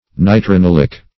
Meaning of nitranilic. nitranilic synonyms, pronunciation, spelling and more from Free Dictionary.
Search Result for " nitranilic" : The Collaborative International Dictionary of English v.0.48: Nitranilic \Ni`tra*nil"ic\, a. [Nitro- + chloranil + -ic.]